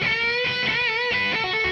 guitar01.wav